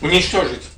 Worms speechbanks
Watchthis.wav